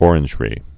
(ôrĭnj-rē, ŏr-)